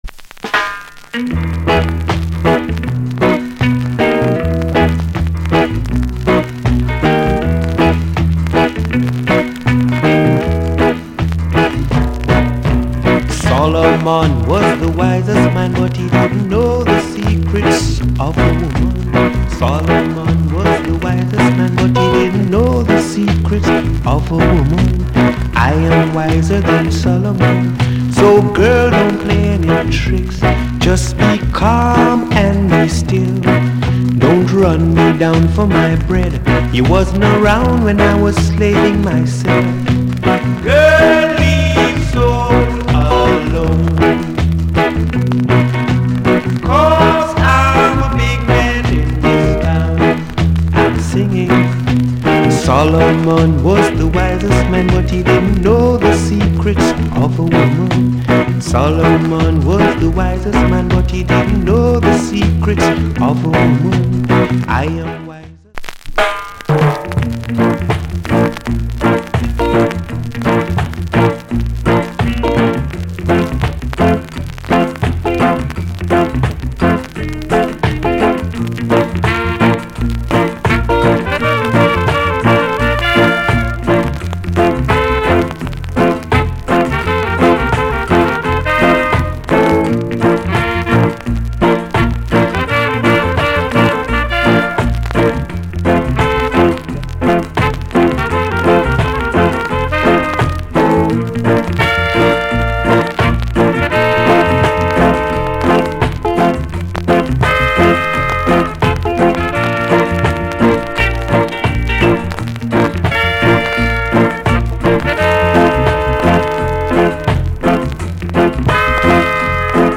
Genre Rock Steady / [A] Vocal Male Vocal [B] Inst